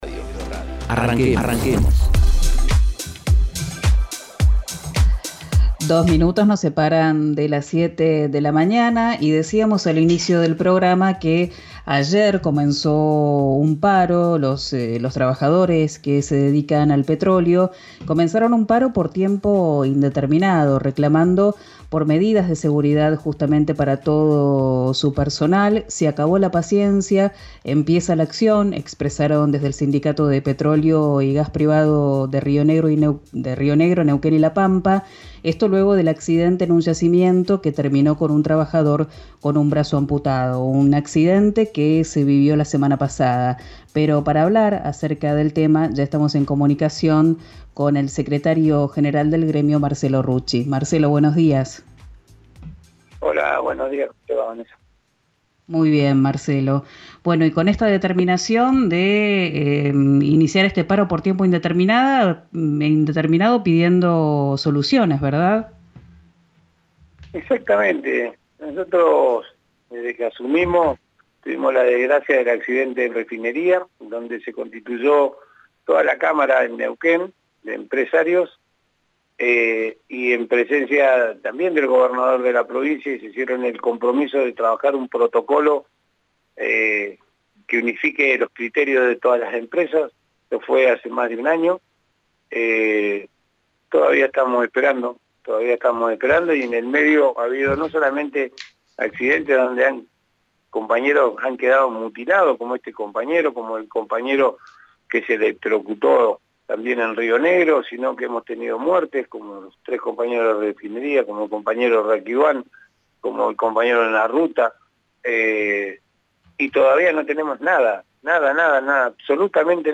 En «Arranquemos» por RÍO NEGRO RADIO, el dirigente petrolero explicó que él había pedido la reunión con la Cámara para primera hora de este lunes, pero le solicitaron que se realice a las 11.